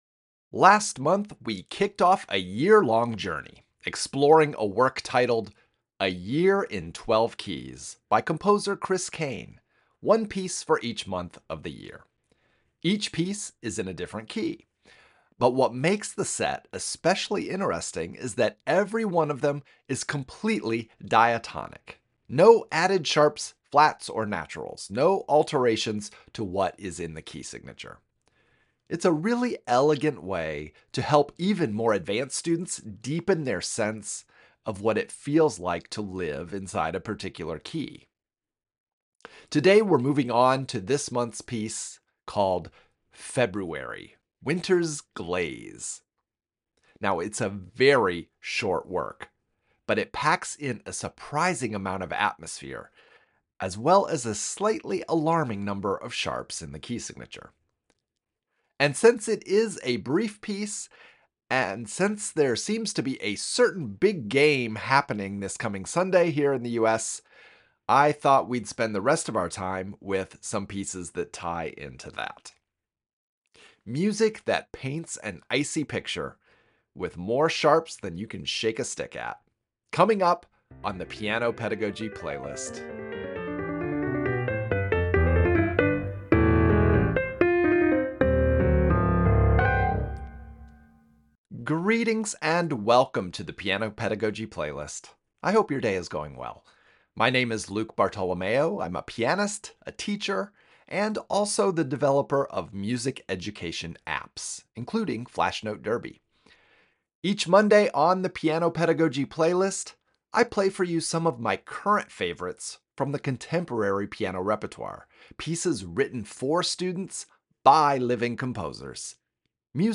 These early-intermediate pieces offer strong pedagogical value — left-hand melody, Alberti bass patterns, legato pedaling, and lively rhythmic work — all wrapped in music full of youthful energy.